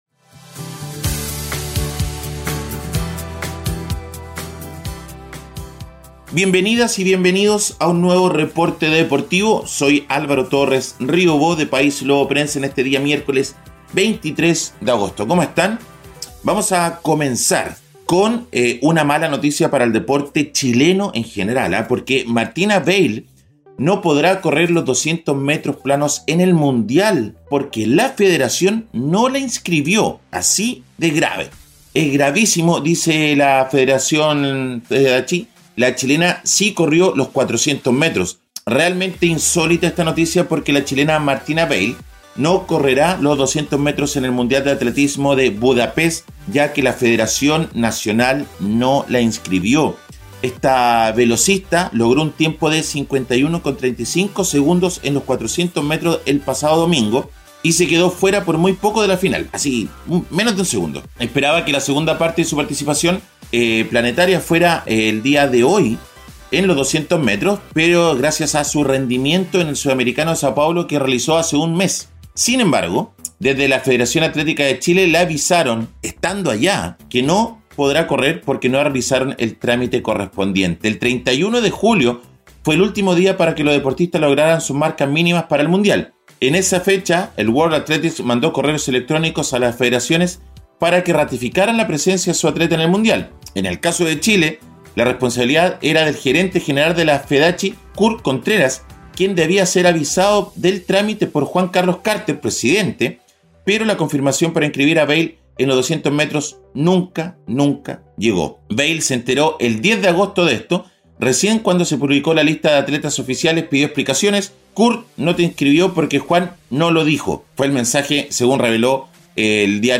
En este episodio, les traemos un breve reporte con las "deportivas" más destacadas de las últimas 24 horas.